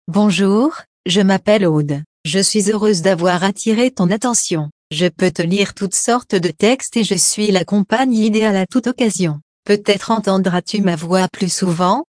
Professionelle Sprachausgabe zum Vorlesen und Vertonen beliebiger Texte
Die Stimmen klingen so natürlich, dass sie von menschlichen Sprechern kaum noch zu unterscheiden sind.
• Die Text-to-Speech Software bietet Ihnen alles, was Sie für die professionelle Vertonung benötigen